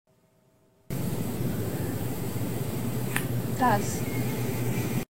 Satisfying AI ASMR Pimple Popper!